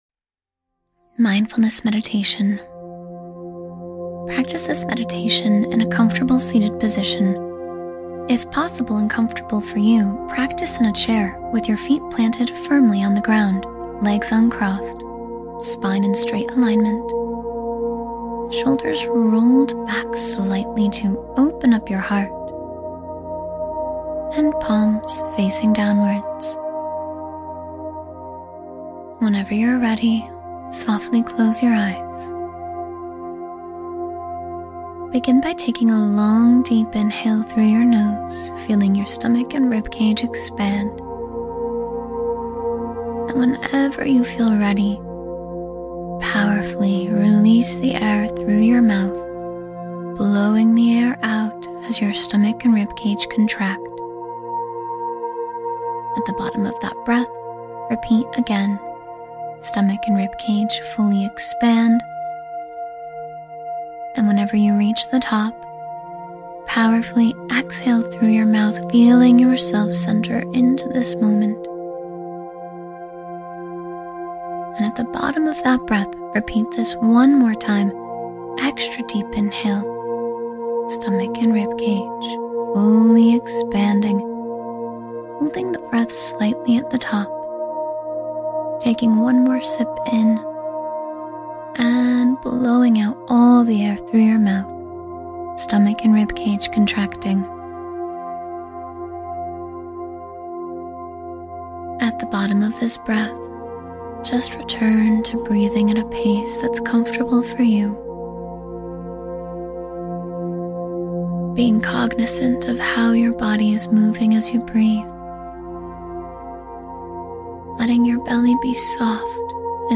Just Be in the Moment—15-Minute Meditation